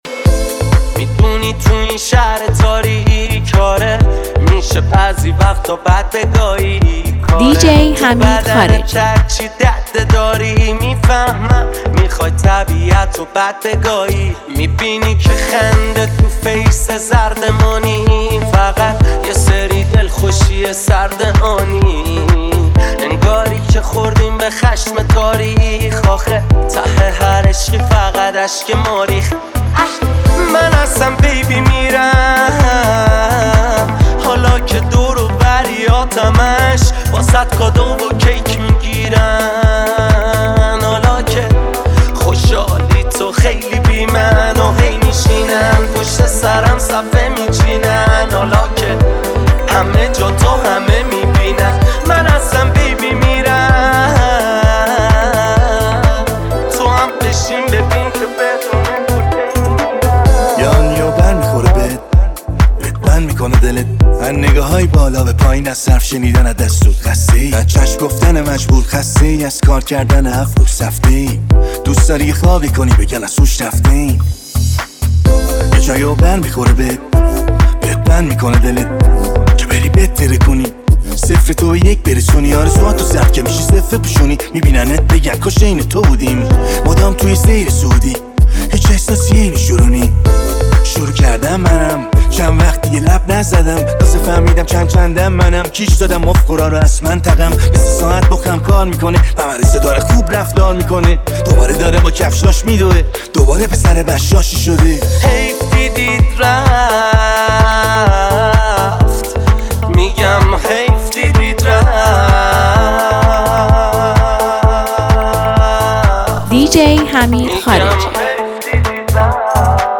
یه ریمیکس فوق‌العاده و پرانرژی برای شما آماده کردیم!
این میکس رپ ترکیبی رو از دست ندید!